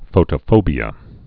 (fōtə-fōbē-ə)